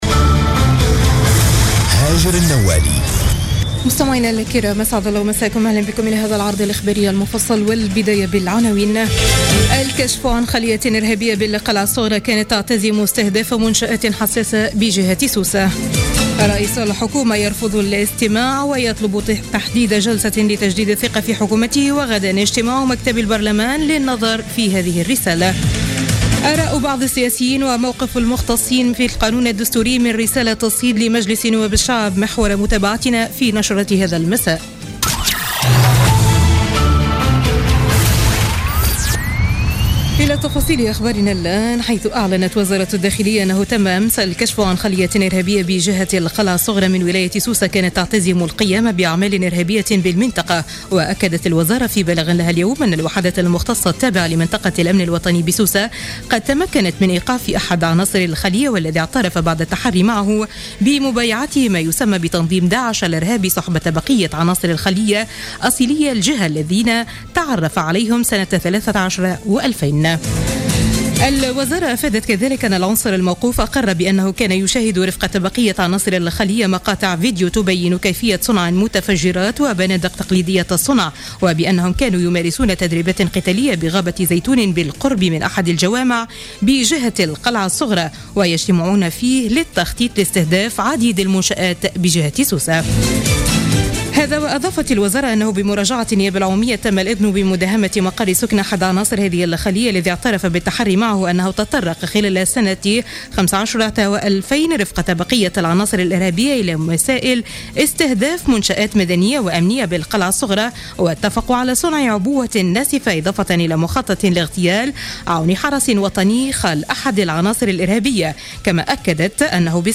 Journal Info 19h00 du mercredi 20 juillet 2016